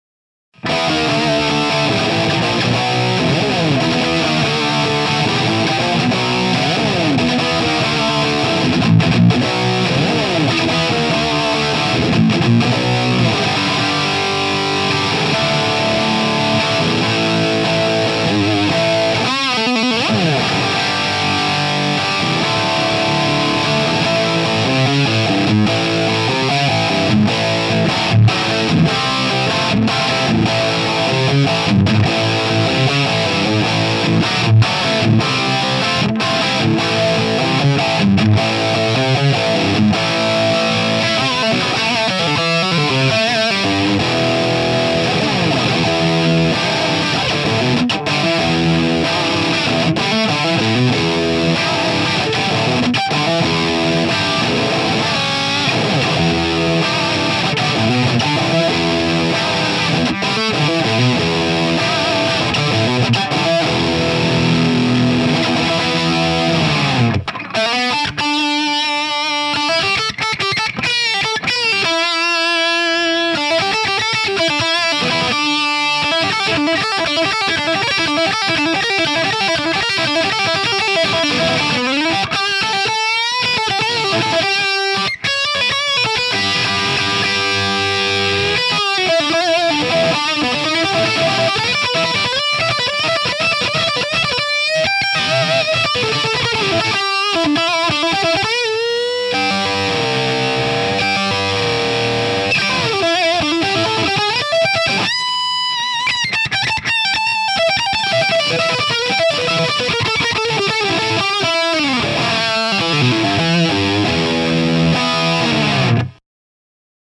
■No.6 　Crunchy Rhythm Tone
G：Gibson Les Paul CUSTOM / Amp：Marshall JCM 800 / NR:OFF US version
高域で圧倒的な存在感、重厚なクランチ・トーン。
Full-bodied crunch tone with ultra-presence in high frequency range.
US version sound clips No.5 - No.11 played by US musician.